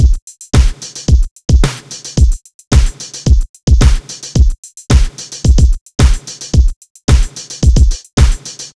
[CC] Ocean Drive (110BPM).wav